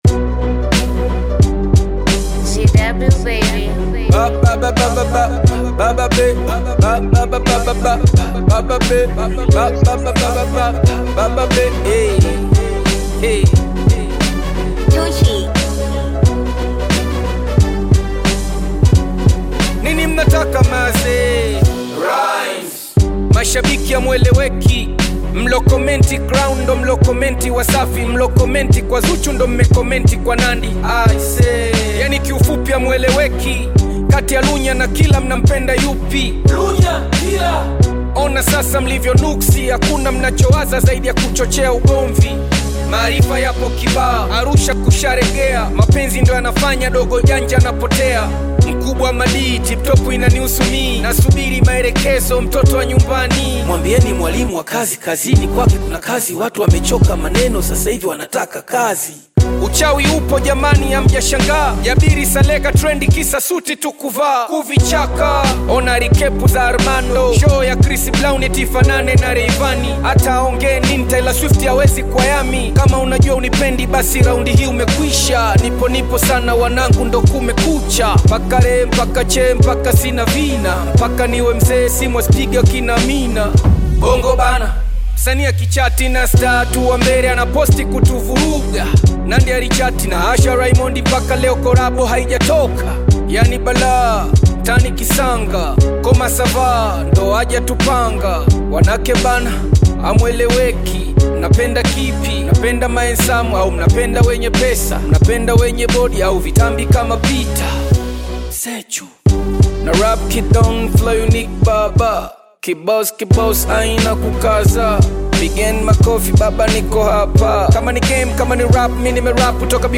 Tanzanian Gospel artist and singer
Bongo Flava You may also like